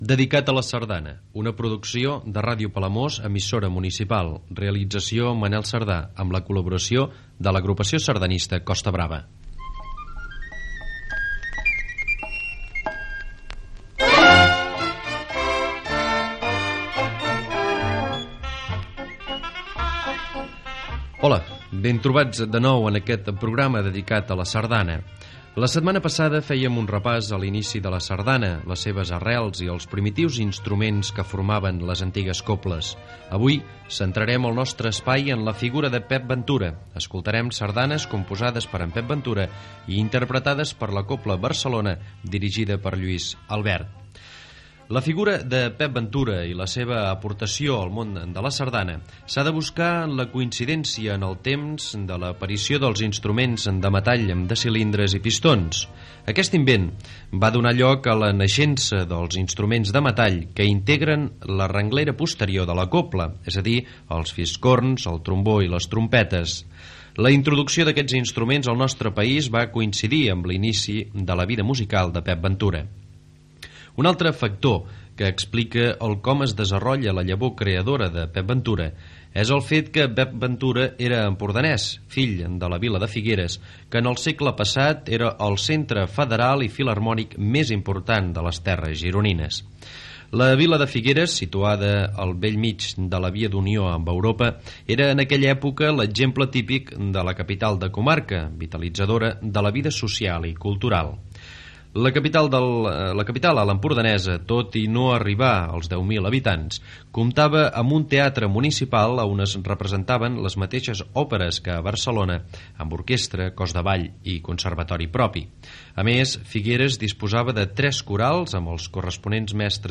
Identificació del programa, presentació, espai dedicat al compositor Pep Ventura
Musical